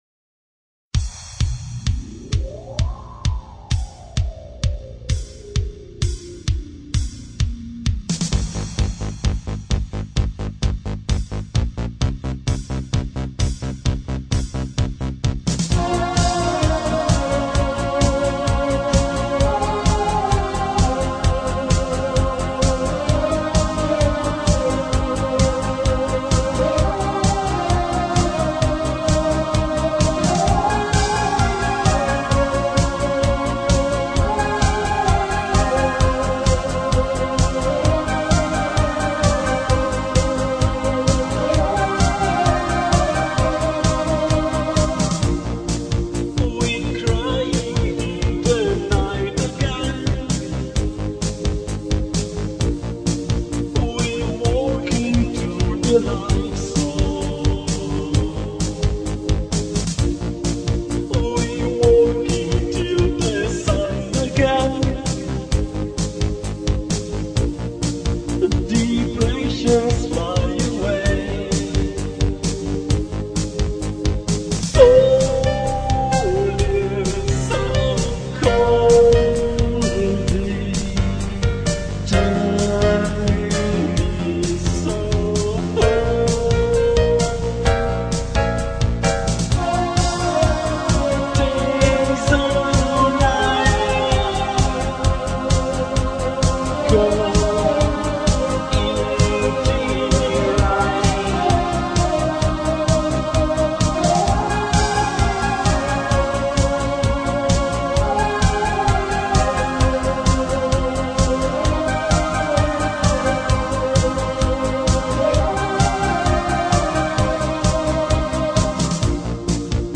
Electronic, Rock
Electro, Goth Rock, Synth-pop